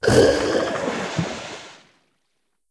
c_seasnake_hit2.wav